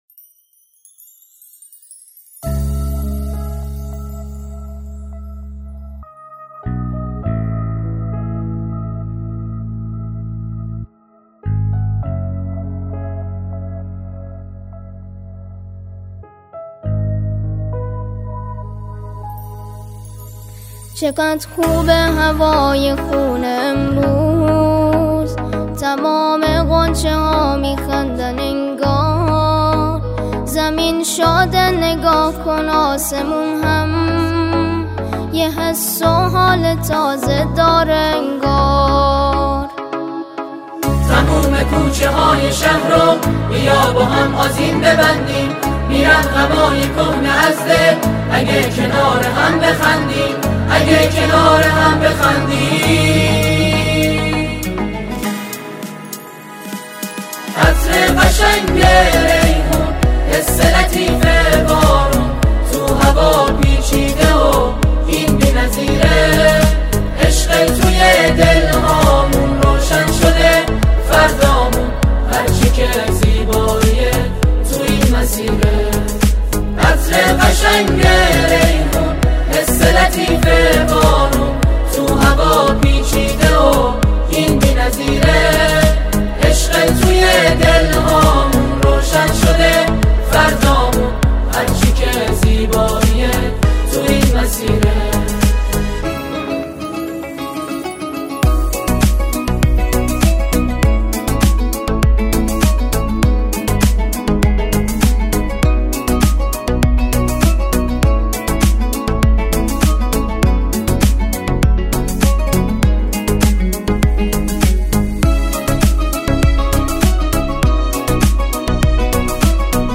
نماهنگ سرود زیبای